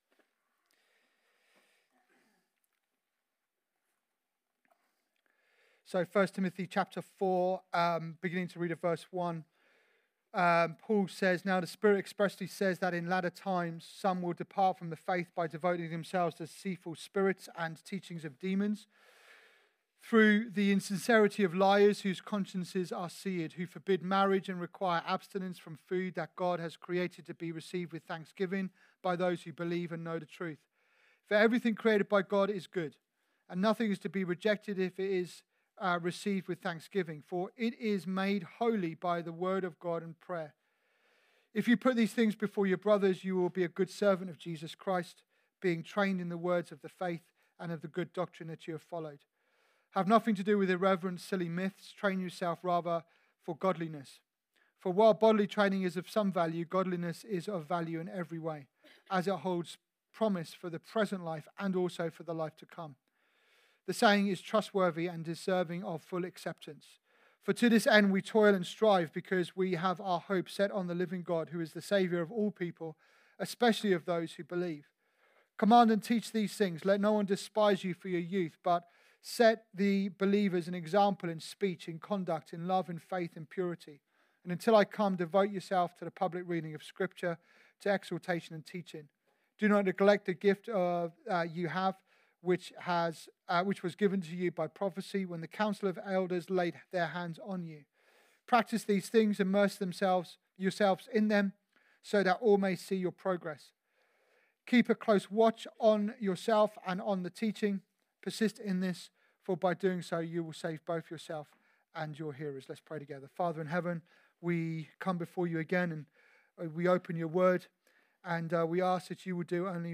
Through this sermon, we’ll unpack: 🔥 Why engaging in God’s mission always stirs spiritual opposition ⚔ How the enemy targets leaders through speech, conduct, love, faith, and purity 🏋‍♂ What it means to “go to the gym” spiritually — training the mind in truth and the heart in holiness 🙏 How gratitude and prayer make creation holy again in a culture of confusion